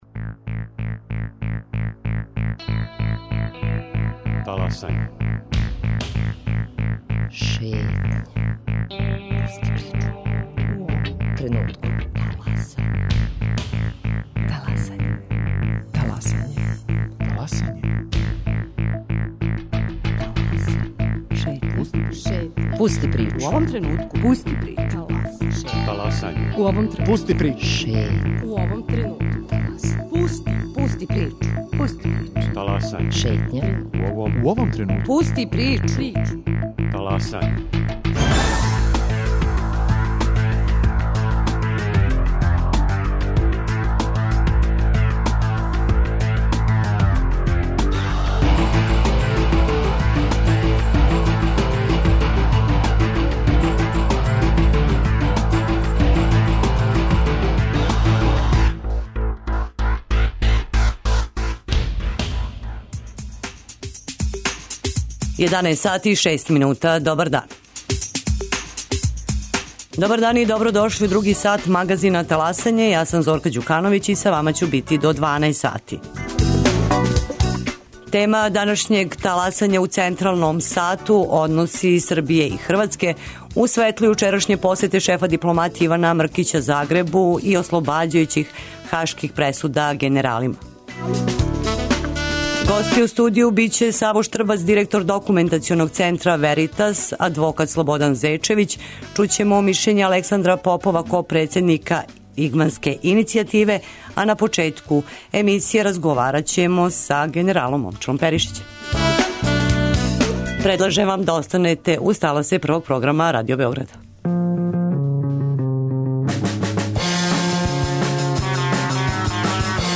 представници Срба у Хрватској